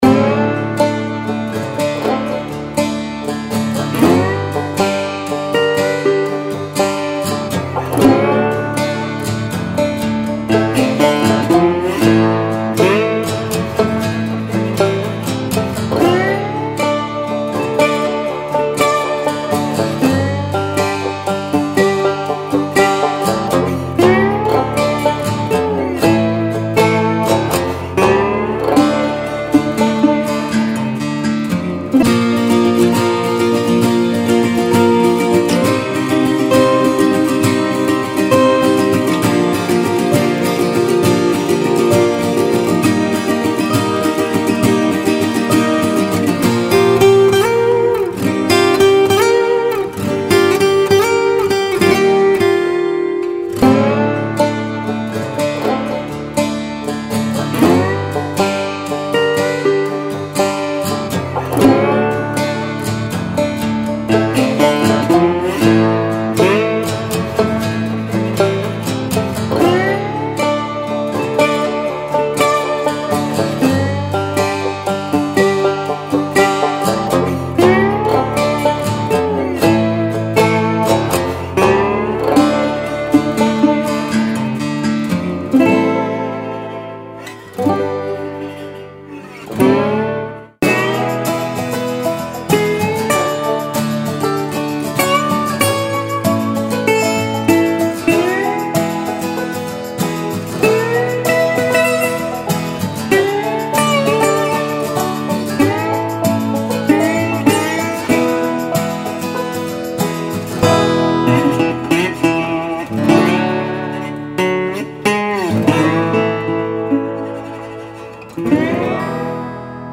This pack features 131 rhythmic and soulful loops played on acoustic guitar, dobro and ganjo and arranged in 4 easy to access loop sets.
Loops can be paired together to add a rich stereo vibe or used in mono tracks to add flavour to your tracks.
Track 1 – 96bpm – E
• 19 x ensemble mixes
Blues-Folk-Songwriter-Demo.mp3